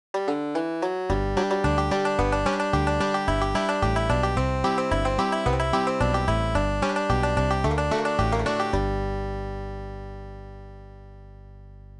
Bluegrass
on lower and upper neck - Scruggs style)